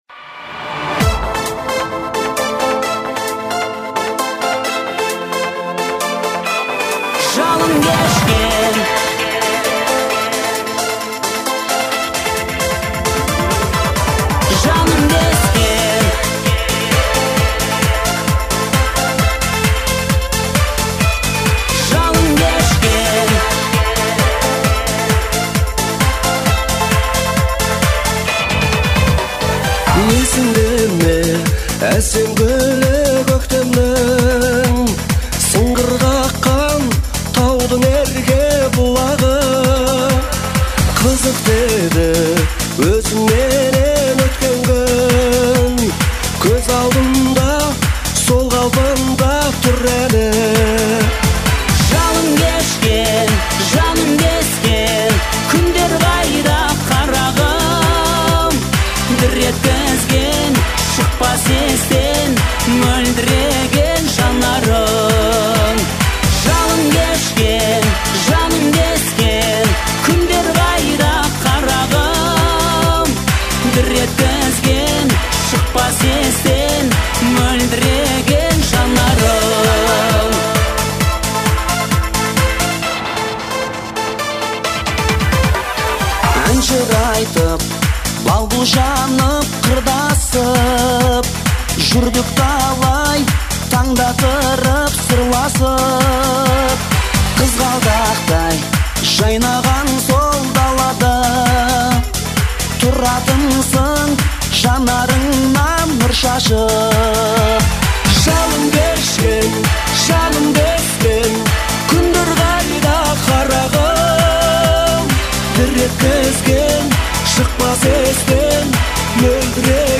выполненная в жанре поп-музыки с элементами этно.